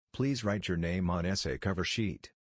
Repeat sentence
• There is no beep sound when the clip ends.
Sample: You will hear a sentence.